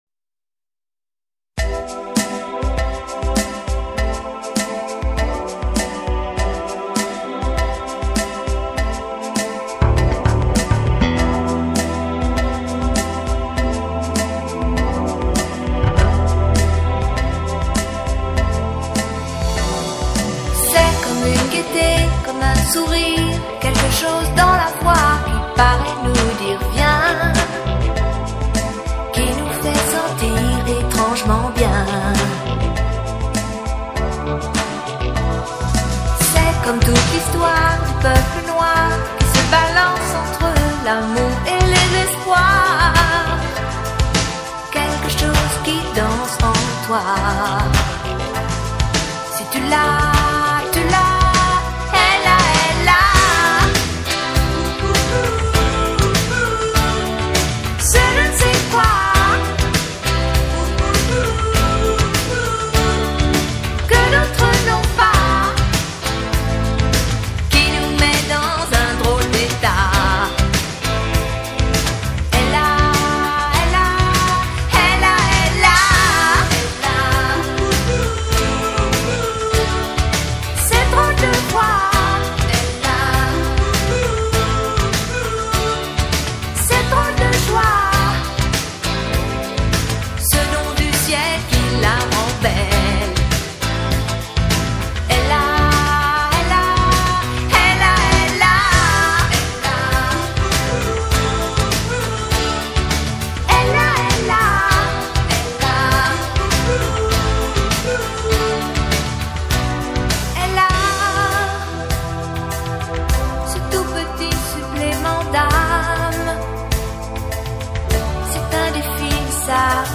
Carpeta: Baile internacional mp3